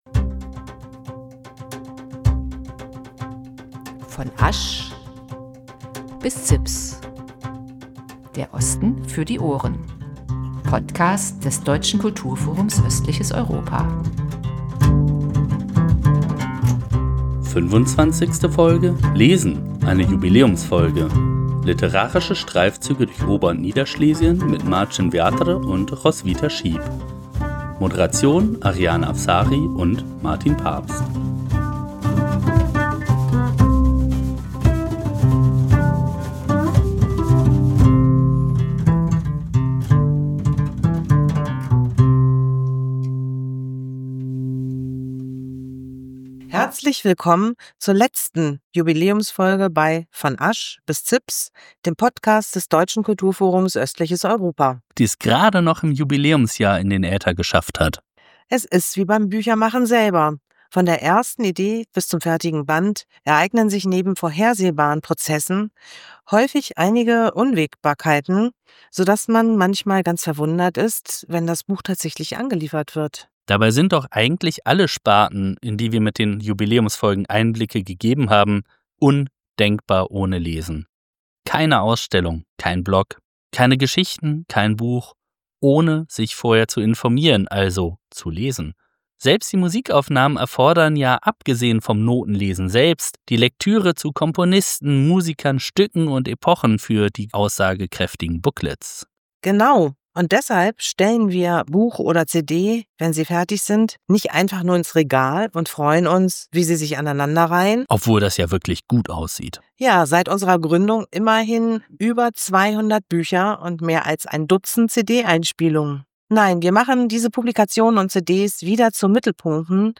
Statt einer Zusammenfassung hören Sie den Live-Mitschnitt der Veranstaltung in der Stadtbibliothek Chemnitz.